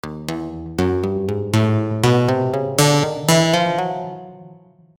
after adjusting the sine period and fade in/out balance